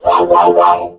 mech_crow_get_hit_02.ogg